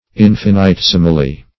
Search Result for " infinitesimally" : The Collaborative International Dictionary of English v.0.48: Infinitesimally \In`fin*i*tes"i*mal*ly\, adv.